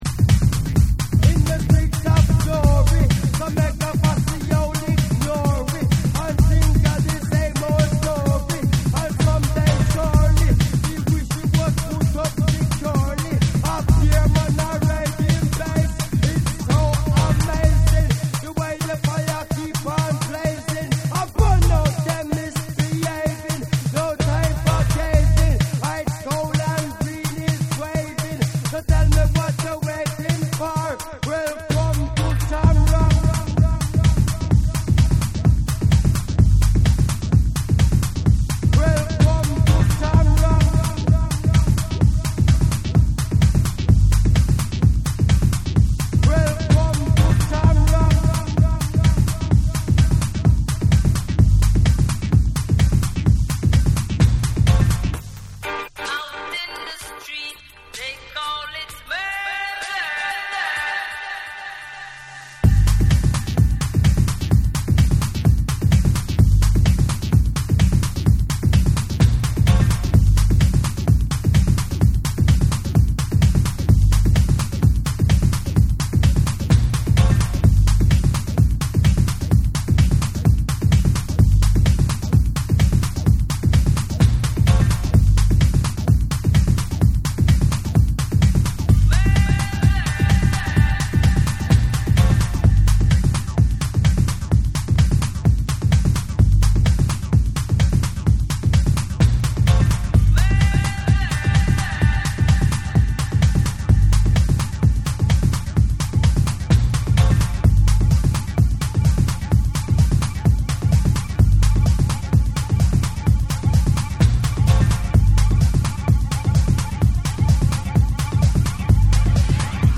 TECHNO & HOUSE / RE-EDIT / MASH UP